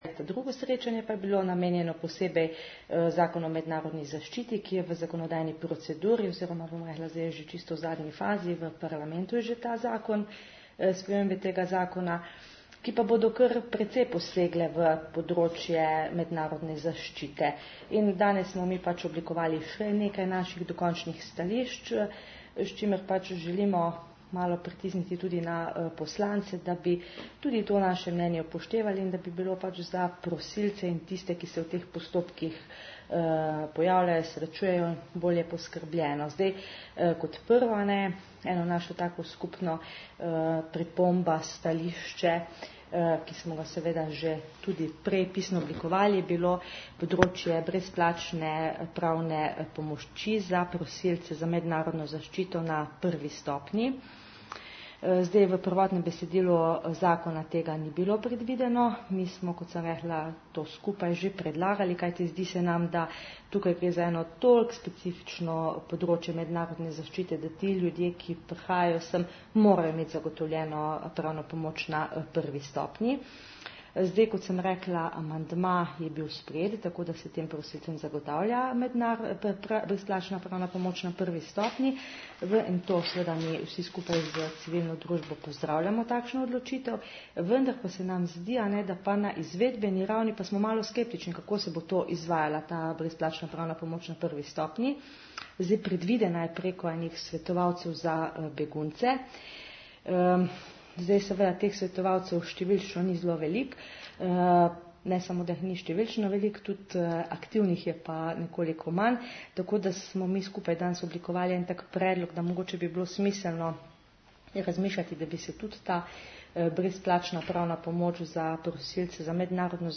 Zvočni posnetki izjave po srečanju (MP3)
Namen tokratnega srečanja, problem brezplačne pravne pomoči - govori mag. Kornelija Marzel, namestnica varuhinje